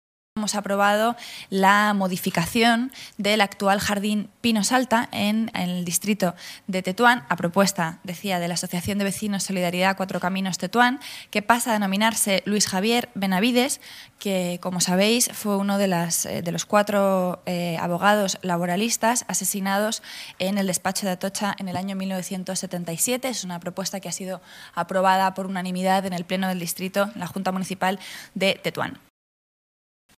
Nueva ventana:Rita Maestre, portavoz Gobierno municipal